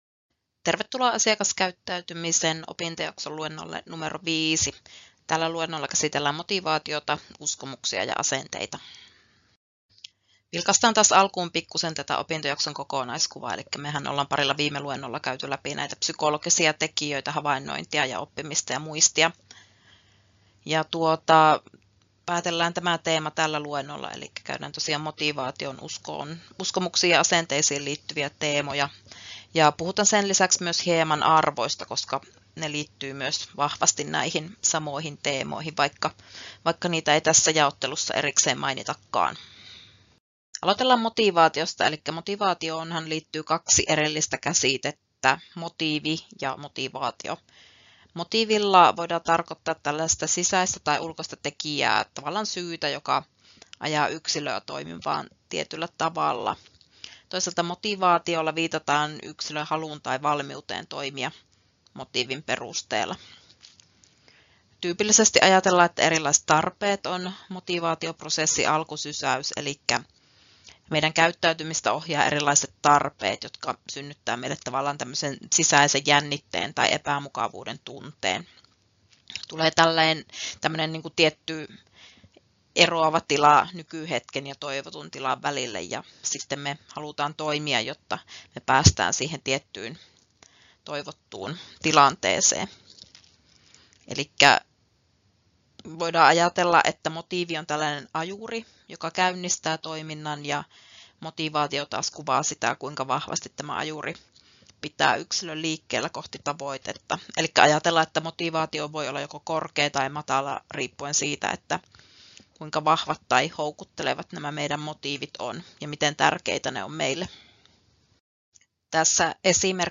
Luento 5: Psykologiset tekijät (motivaatio, uskomukset ja asenteet — Moniviestin